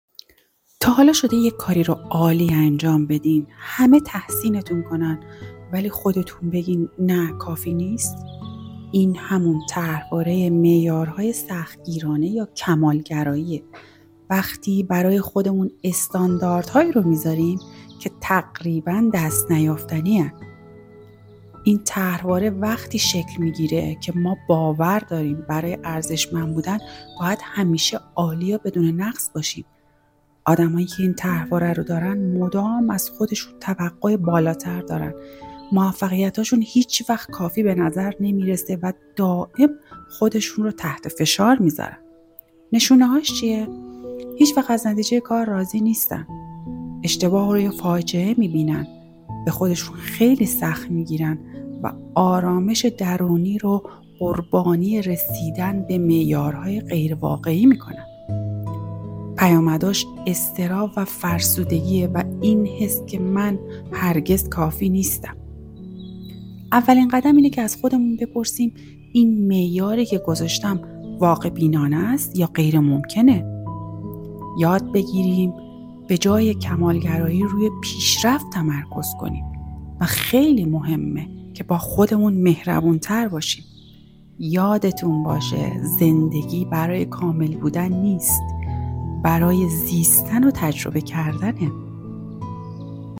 چه صدای آرام بخشی و اینکه چه نکات مهمی رو گفتن...